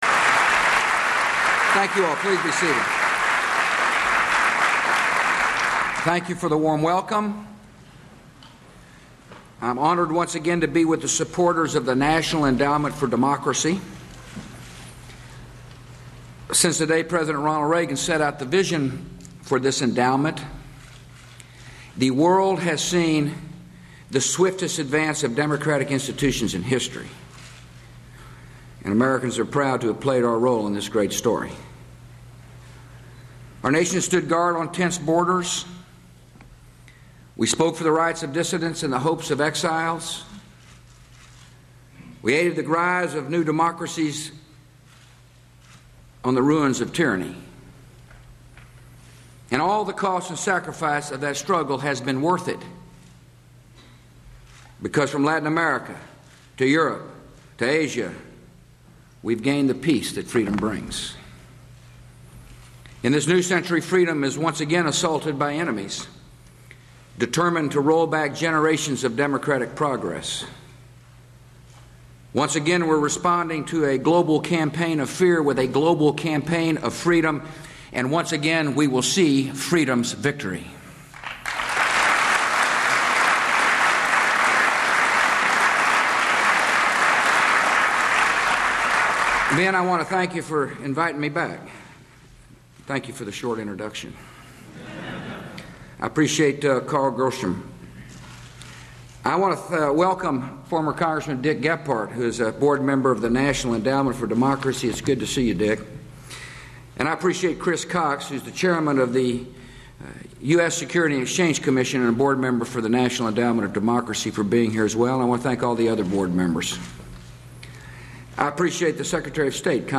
U.S. President George W. Bush speaks at a National Endownment for Democracy event